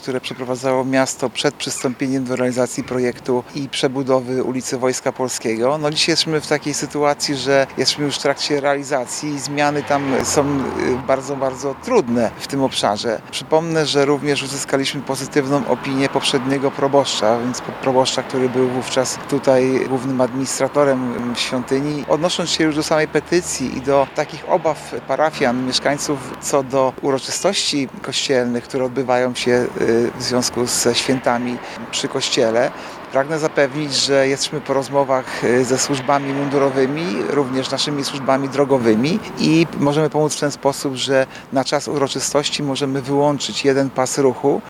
O sprawie rozmawiamy z Arturem Urbańskim, zastępcą prezydenta Ełku.